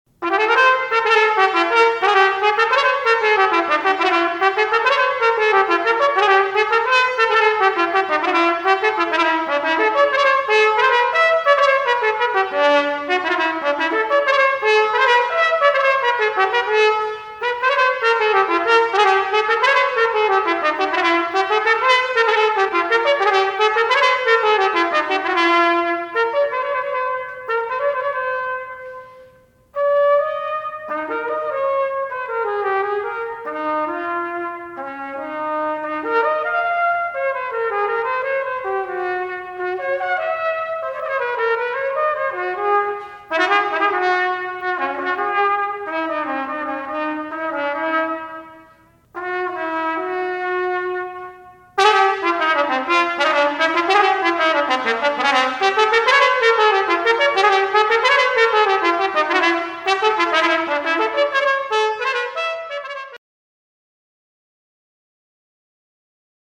for Bb Trumpet Unaccompanied
With energy